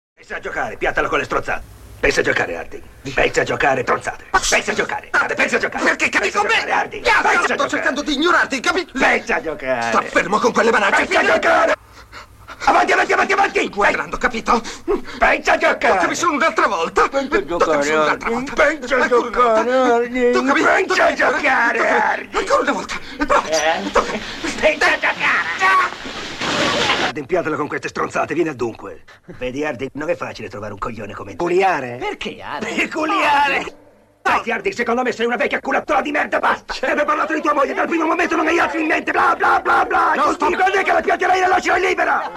voce di Enzo Robutti nel film "Qualcuno volò sul nido del cuculo", in cui doppia Christopher Lloyd.